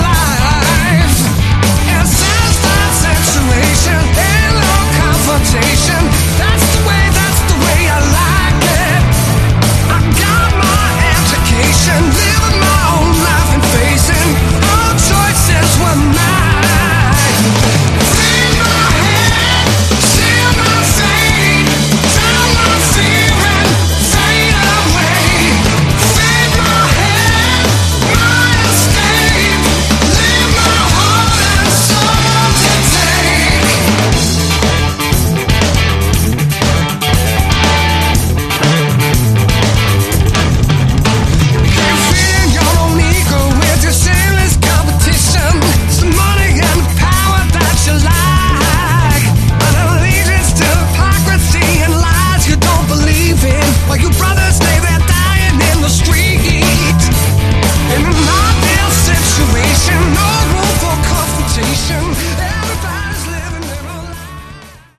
Category: Hard Rock
vocals, guitar, mandolin
keyboards
bass
drums, percussion
additional backing vocals